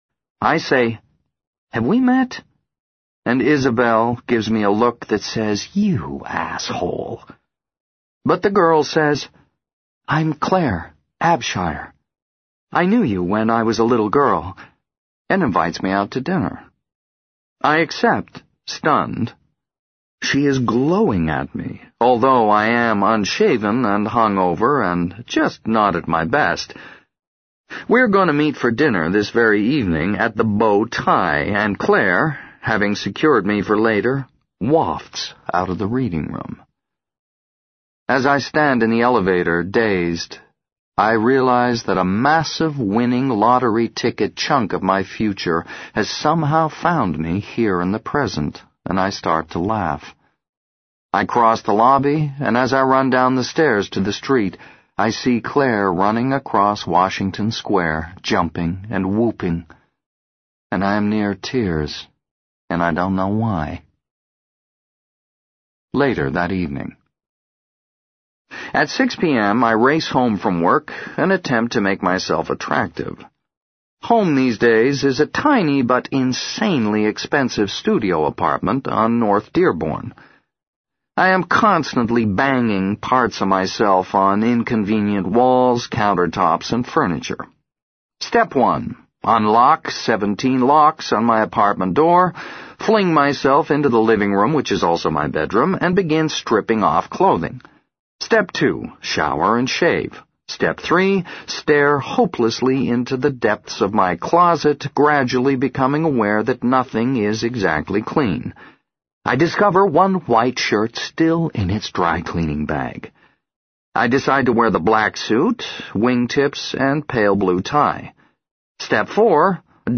在线英语听力室【时间旅行者的妻子】08的听力文件下载,时间旅行者的妻子—双语有声读物—英语听力—听力教程—在线英语听力室